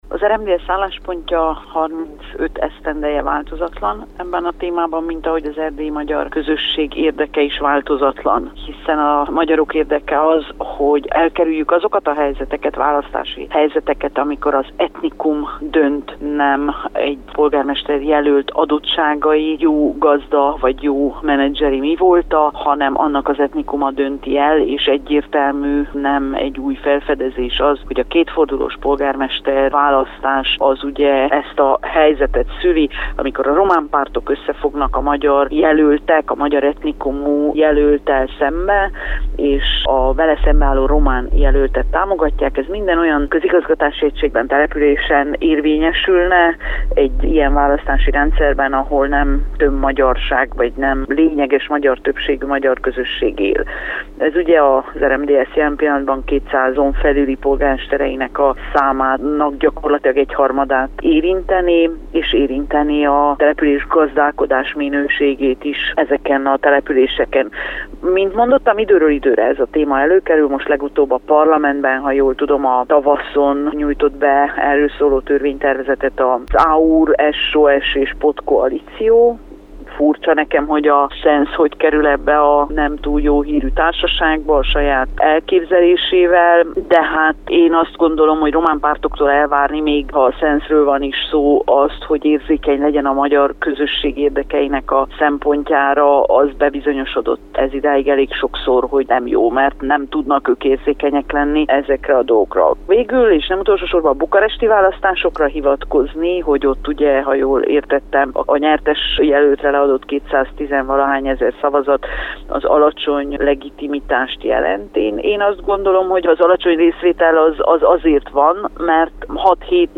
Politikusokat és politológust is megkérdeztünk a témában.